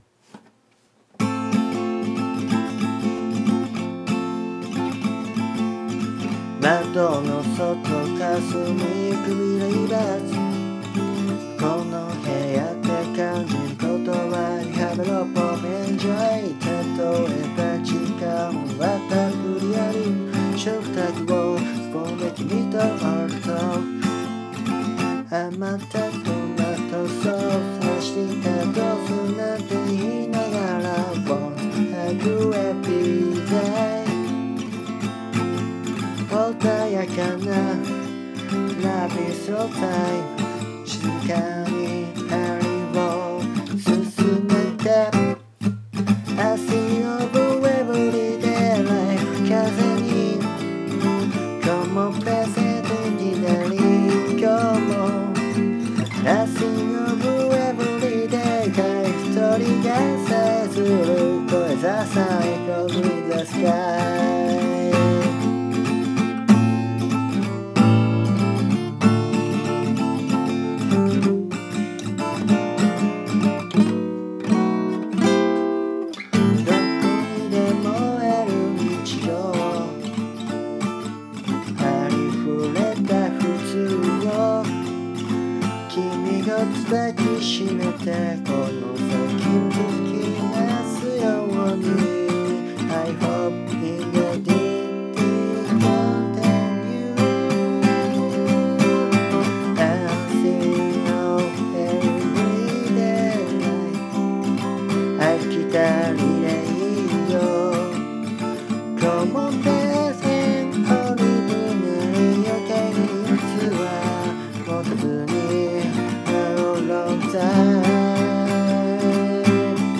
弾き語りやけど。
ほぼ弾きながら歌ってみたままに録ってるからまだ特に固まってないんだよね。